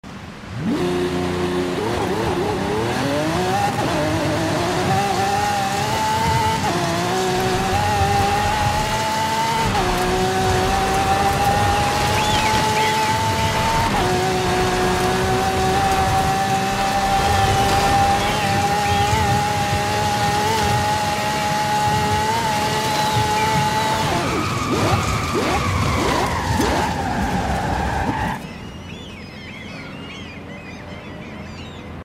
2016 Lamborghini Centenario LP 770 4 Sound Effects Free Download